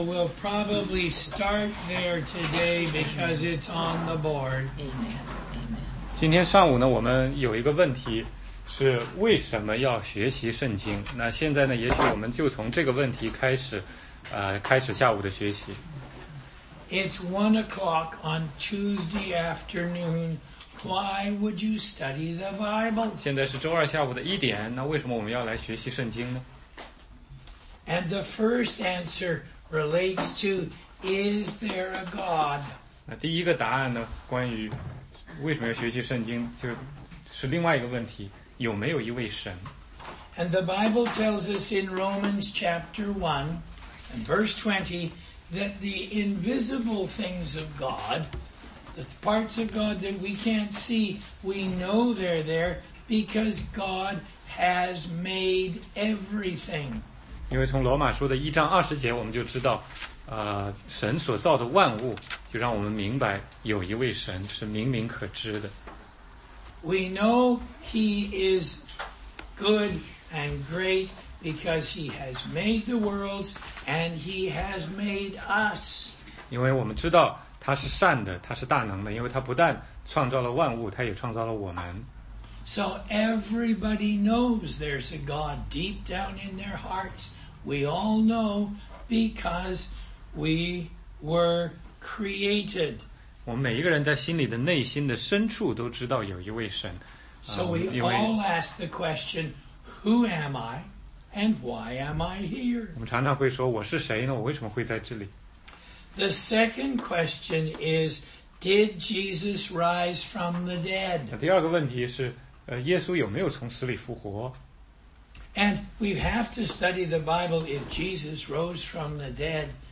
答疑课程